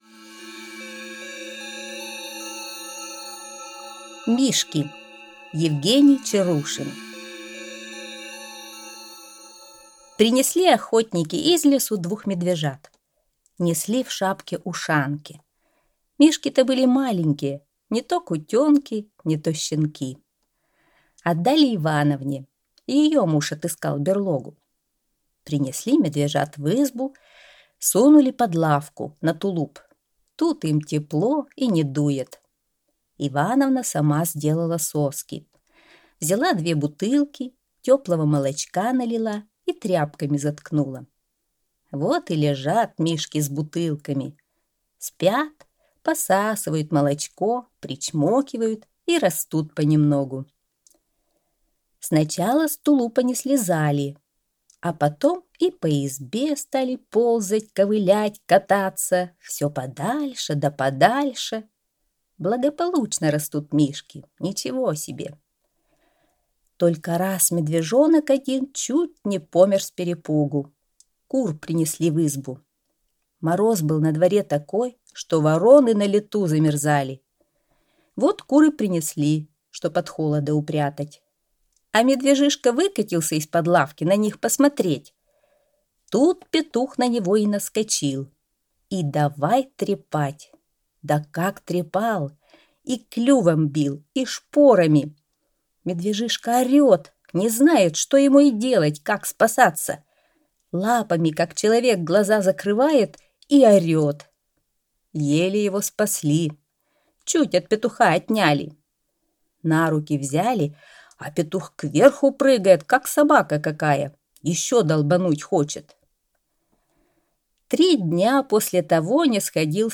Аудиорассказ «Мишки»